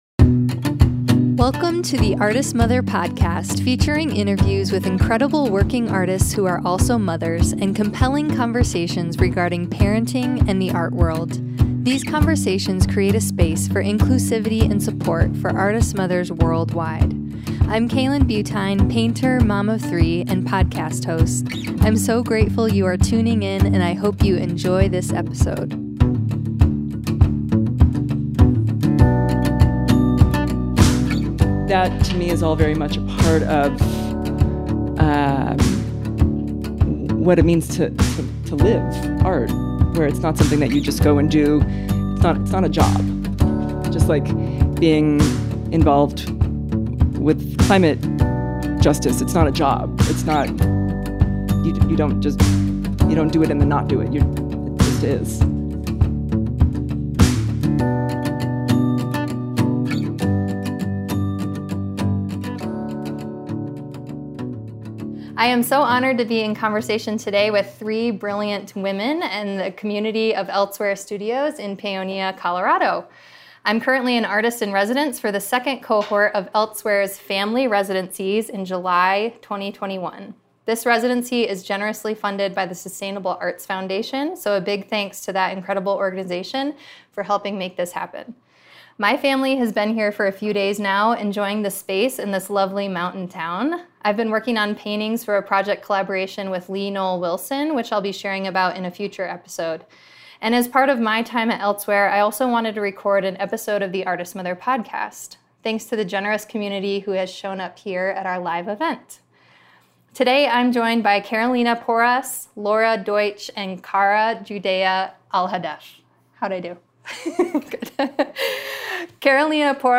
Panel at Elsewhere Studios
102-AM-Paonia-Panel.mp3